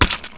stumphit.wav